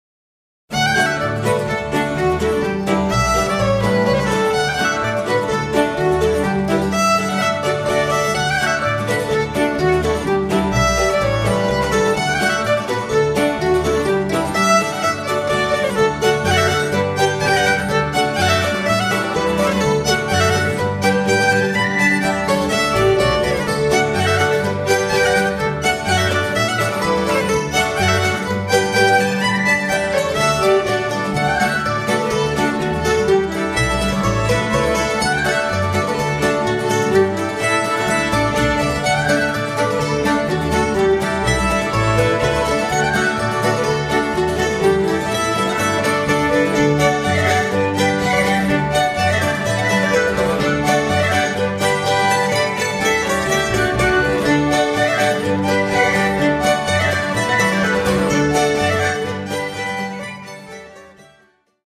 Zweitritt = "Ein höchst einfacher, aber unschöner Rundtanz im raschen Zweivierteltakt; die Tanzenden haben einander die Hände auf die Achseln gelegt, der Tänzer dabei den rechten Fuß zwischen die Füße seiner Tänzerin gestellt, und in dieser Gestalt drehen sie sich rasch bis zur Erschöpfung herum.
Schottische Polka im Rhythmus eines 'Zweitritts' (Denis Murphy's Polka, gespielt von der Schweizer Gruppe Slainte).